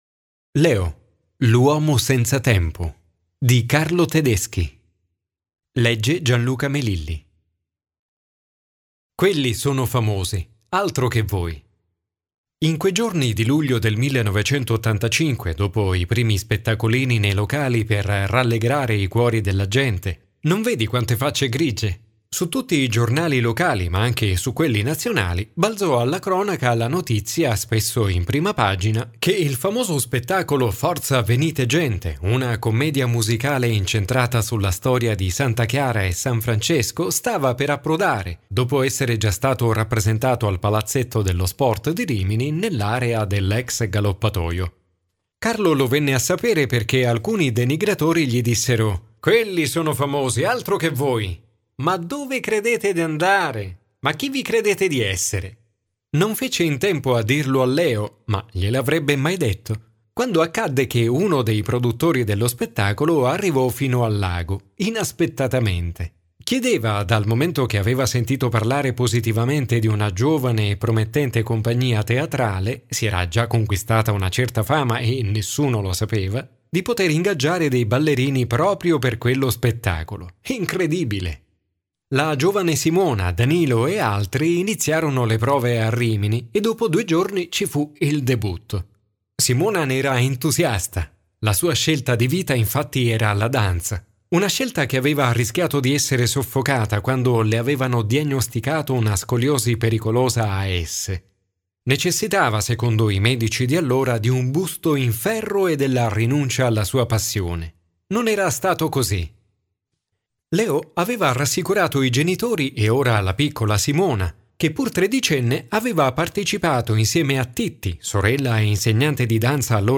Scarica l'anteprima del libro Leggi l'intervista a Carlo Tedeschi Ascolta l'incipit del romanzo